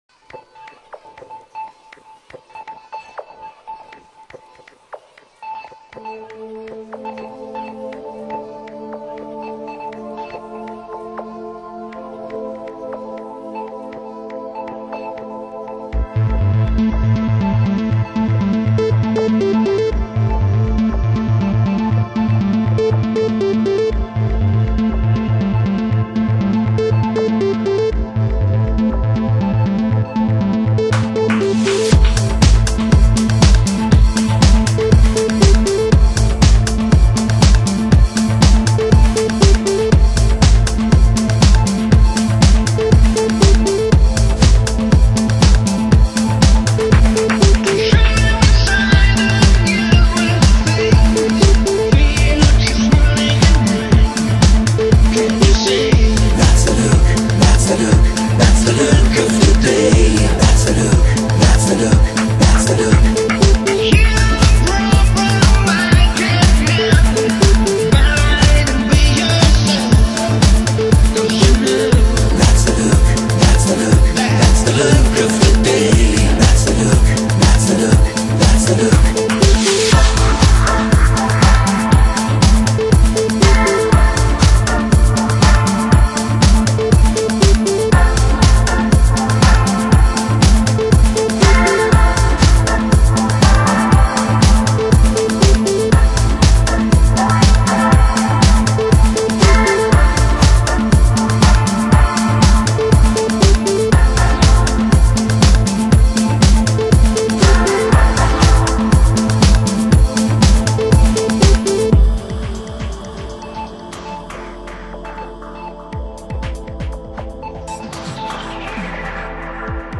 是“NEW AGE MUSIC”的代表。